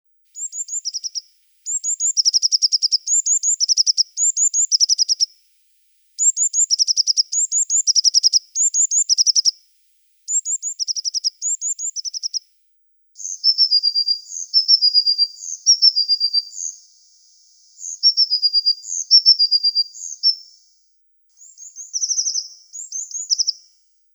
Звуки синицы
Звуки диких птиц в лесу